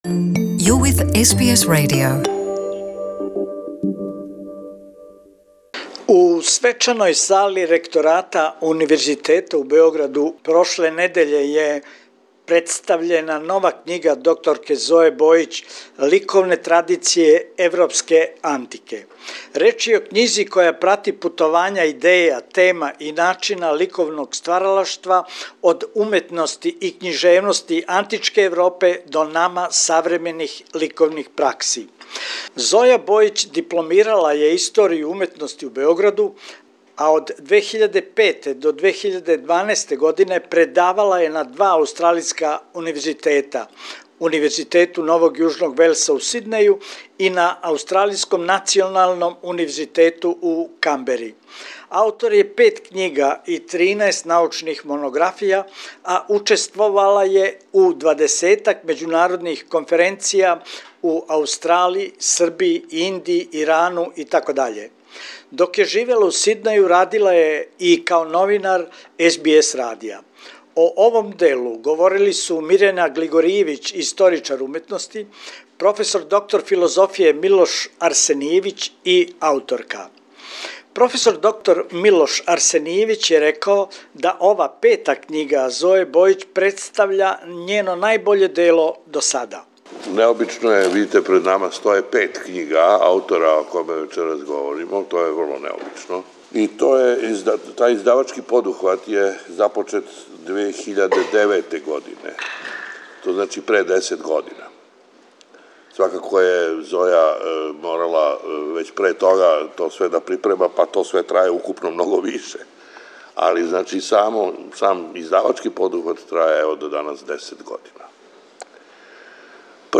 У свечаној сали Ректората Универзитета у Београду представљена је нова књига